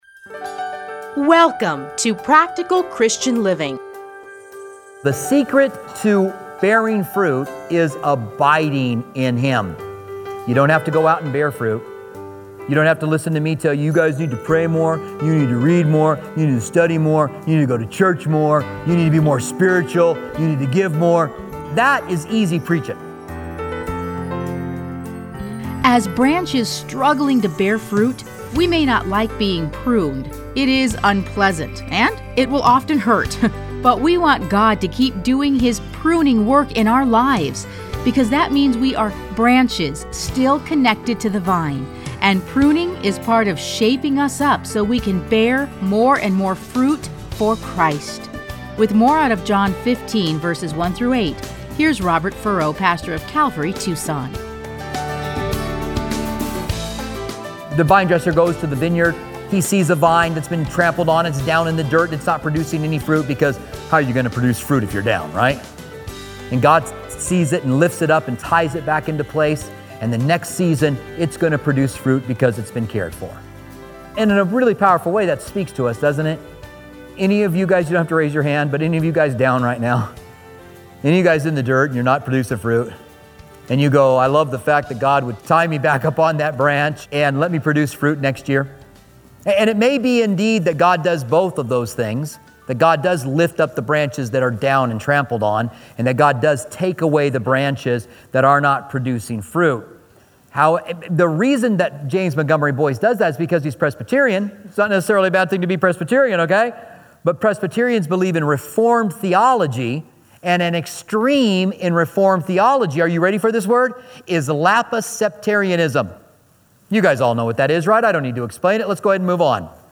Listen to a teaching from John John 15:1-8.